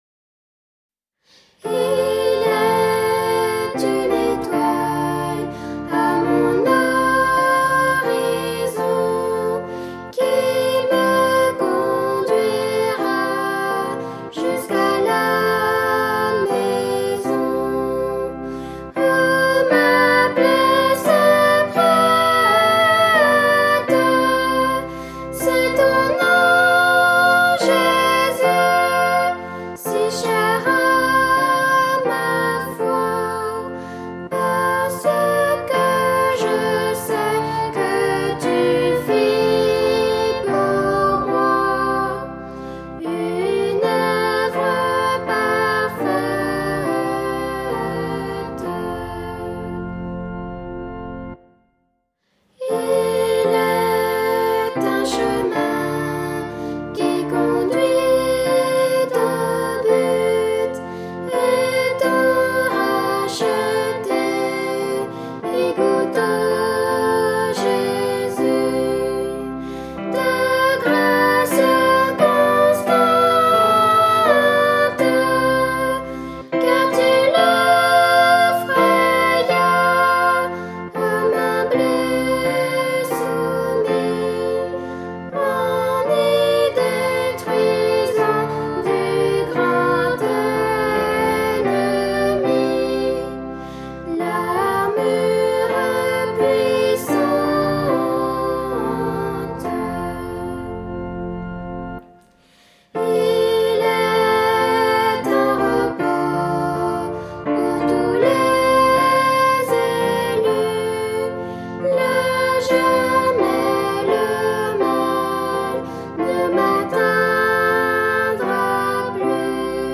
Un nouveau cantique chanté par une famille chrétienne : « Il est une étoile » !